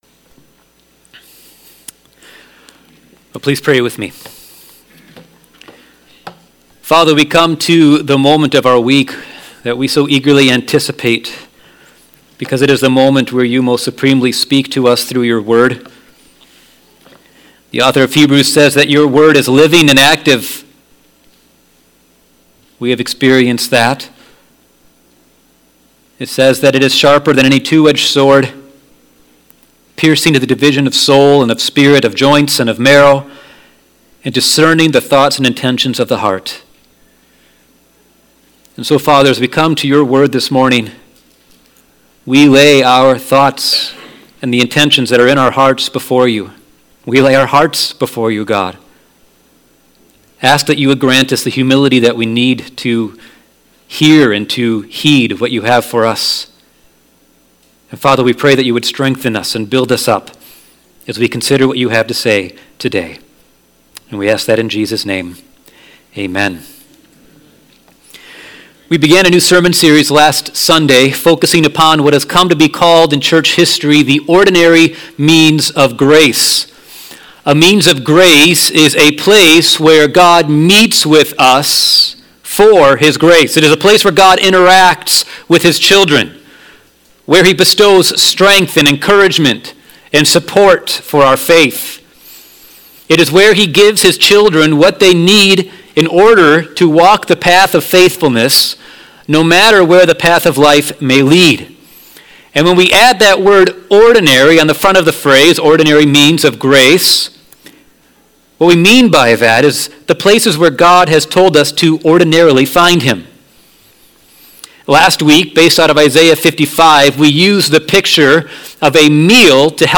Sermons | Rothbury Community Church